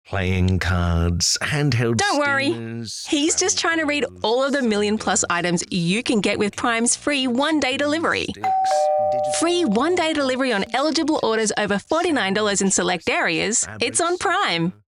Home / Work / Voiceover / Amazon Prime